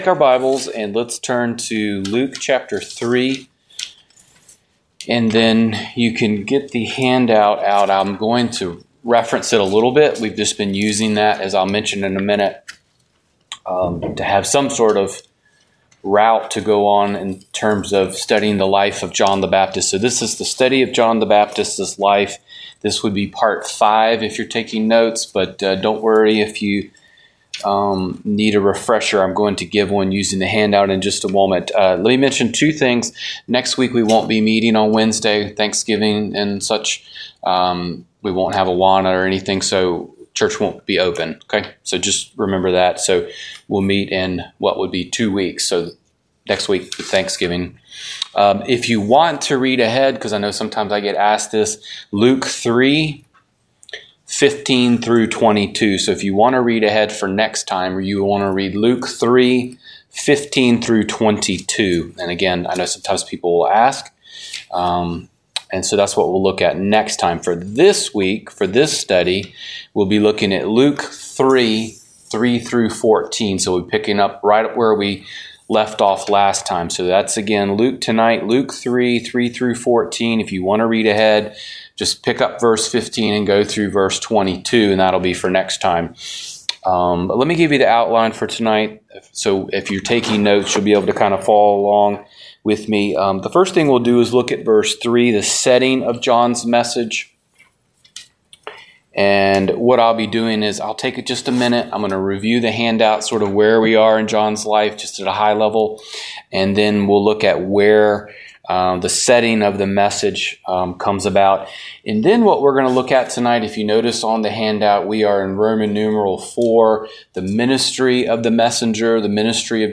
Download Download The Life of John the Baptist - Part 5 Wed. Night Bible Study Matthew 23:37-39 "The King's Lament" Wed.